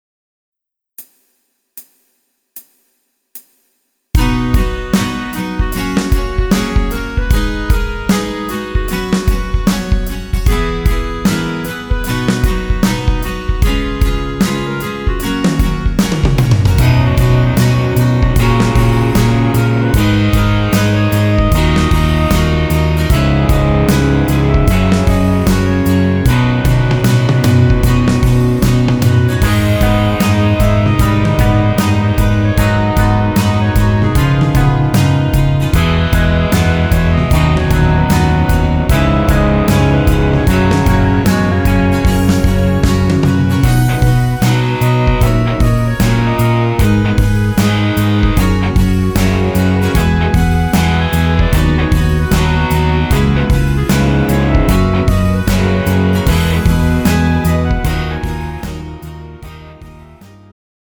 음정 남자-1키
장르 축가 구분 Pro MR